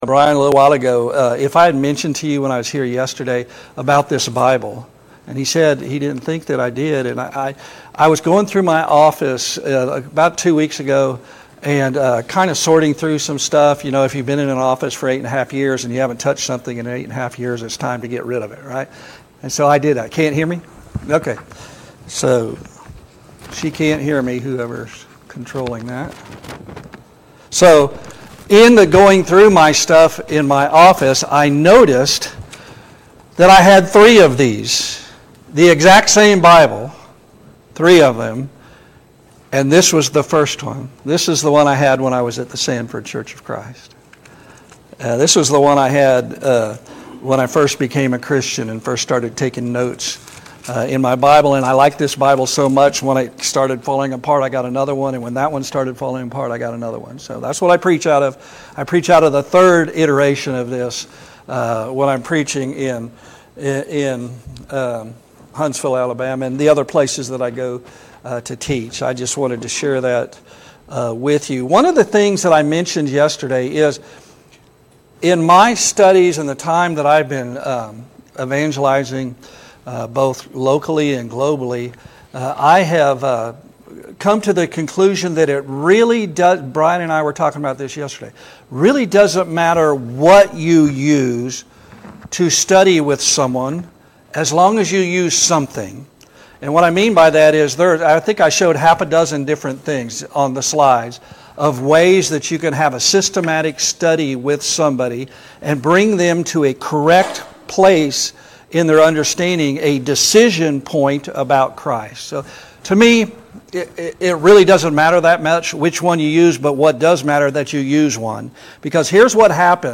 2 Timothy 2:15 Service Type: Gospel Meeting « 3.